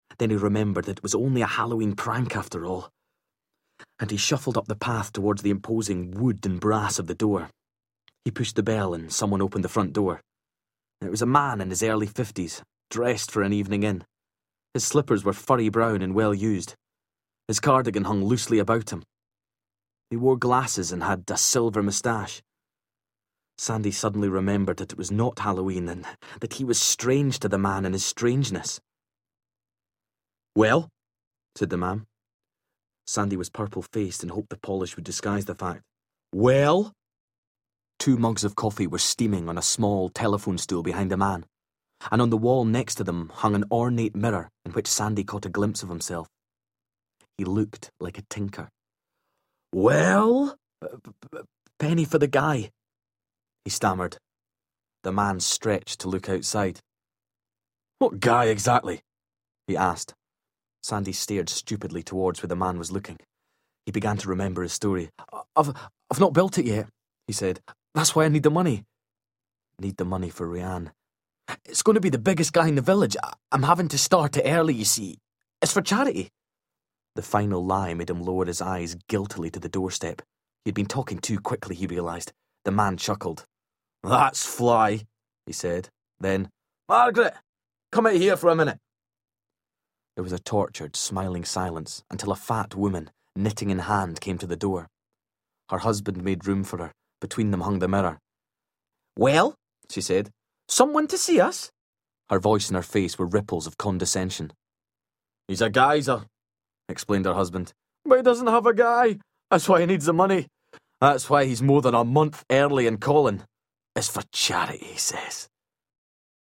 Talking+Book+Sample.mp3